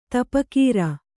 ♪ tapakīra